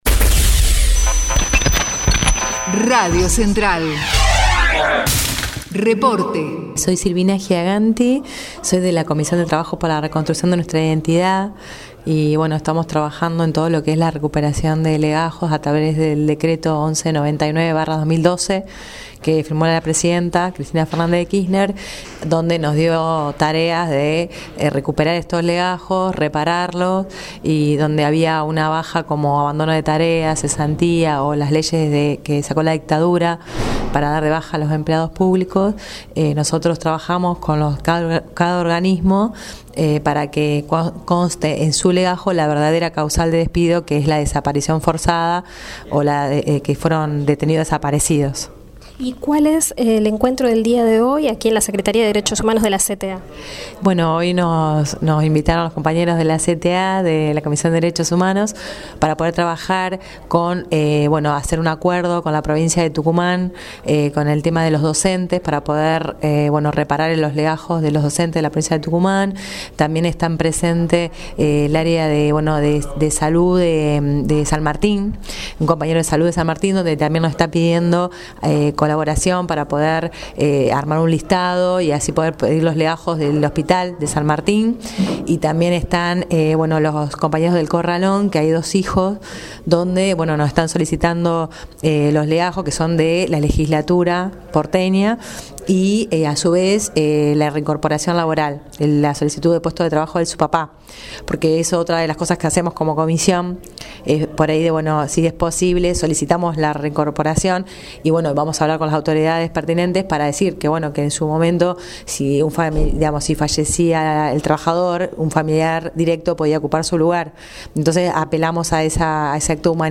Encuentro en la secretaria de Derechos Humanos de la CTA